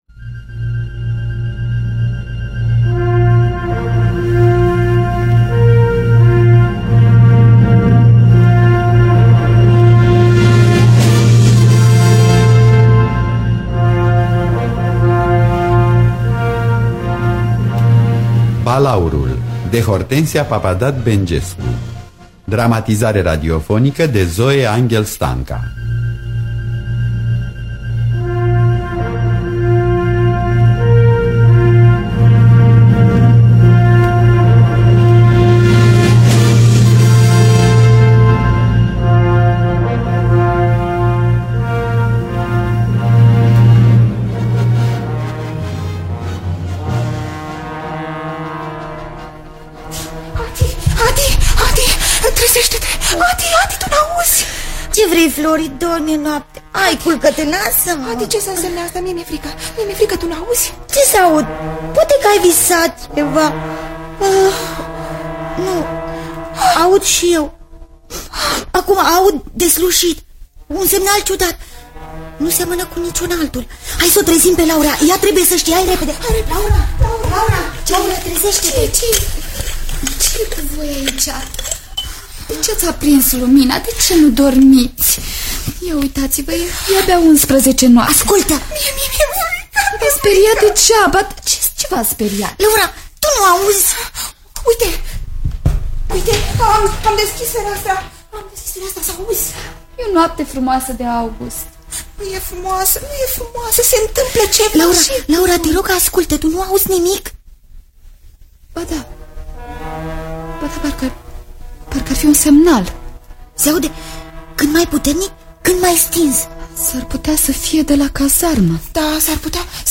Adaptarea radiofonică de Zoe Anghel Stanca.